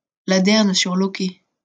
来自 Lingua Libre 项目的发音音频文件。 语言 InfoField 法语 拼写 InfoField Ladern-sur-Lauquet 日期 2020年6月3日 来源 自己的作品
pronunciation file